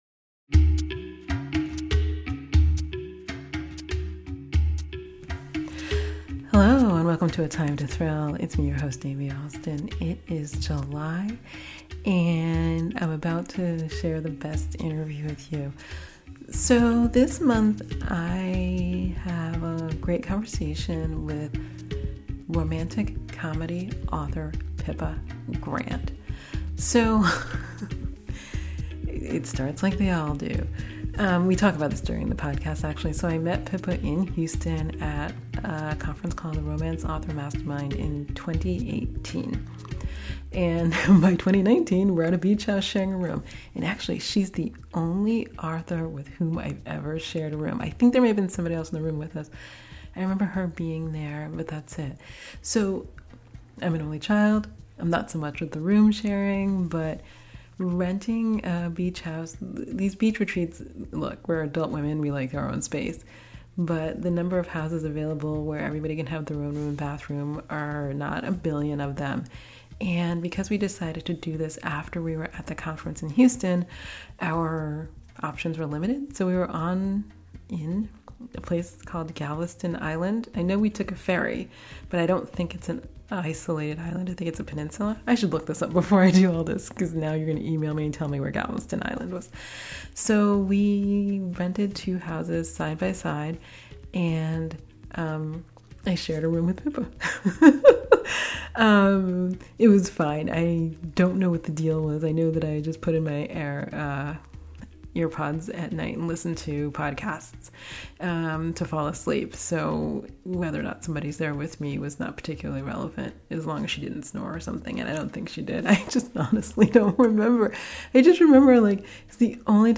Episode 48: A Time to Thrill – Conversation